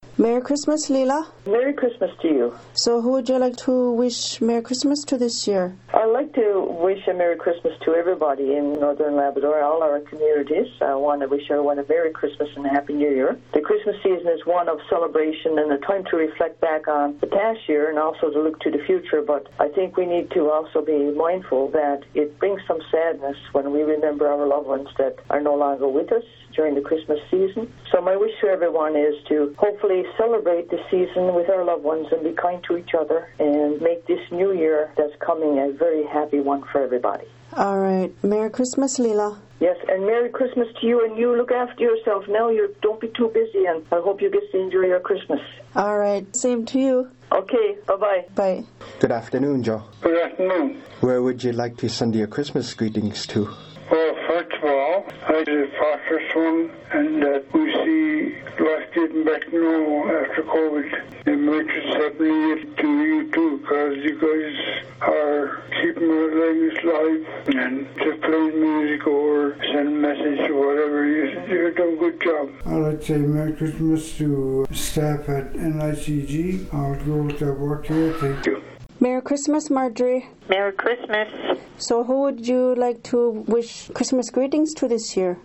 OKâlaKatiget Radio will be airing the first recorded Christmas greetings from businesses and some senior’s this year.